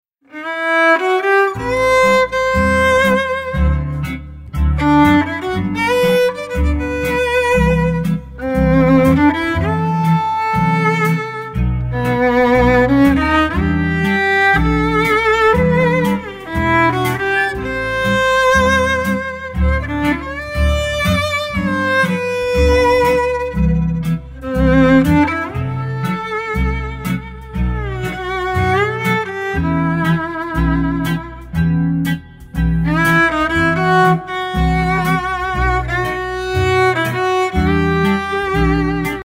Traditional Italian Instrument
Violin
Audio file of the Violin
Violin.mp3